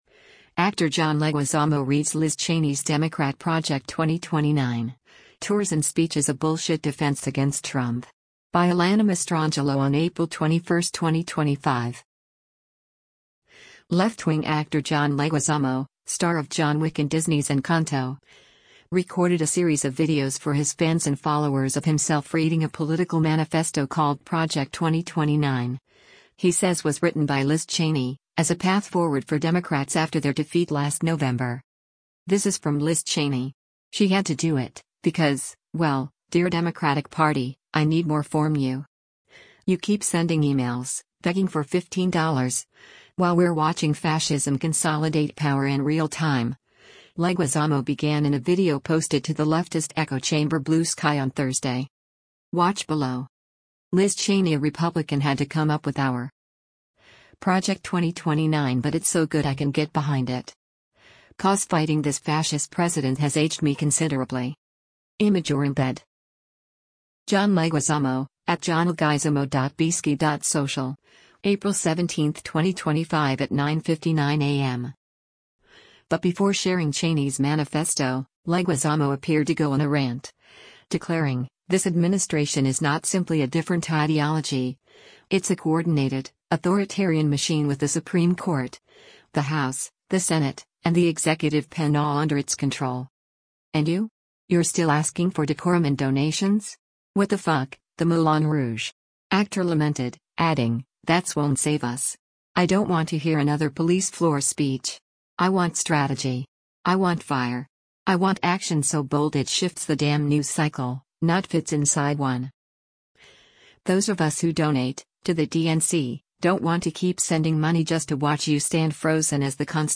Actor John Leguizamo Reads Liz Cheney’s Democrat ‘Project 2029’: Tours and Speeches a ‘Bulls**t’ Defense Against Trump
Left-wing actor John Leguizamo, star of John Wick and Disney’s Encanto, recorded a series of videos for his fans and followers of himself reading a political manifesto called Project 2029, he says was written by Liz Cheney, as a path forward for democrats after their defeat last November.